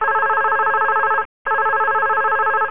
OFFICE SOUND EFFECTS
Phone 2
phone2.wav